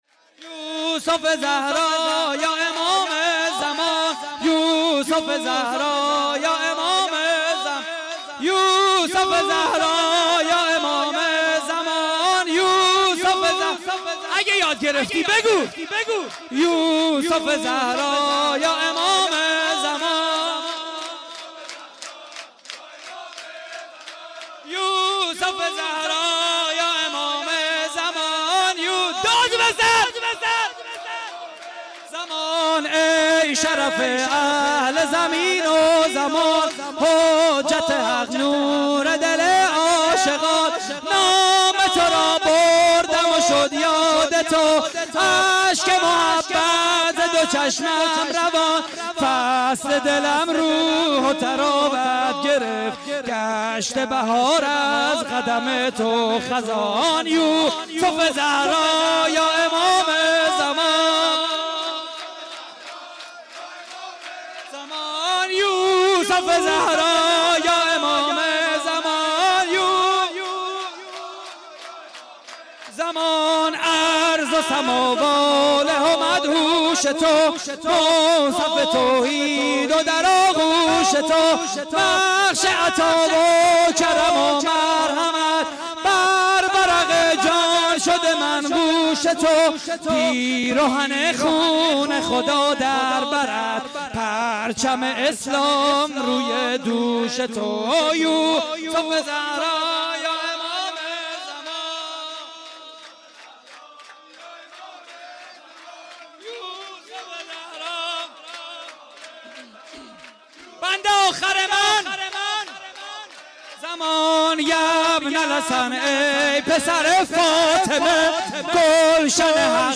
جشن ولادت امام زمان (عج) / هیئت الزهرا (س)؛ نازی آباد - 1 اردیبهشت 98
صوت مراسم:
شور: ای شرف اهل زمین و زمان؛ پخش آنلاین |